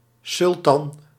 Ääntäminen
US : IPA : [ˈpeɪs.tɹi]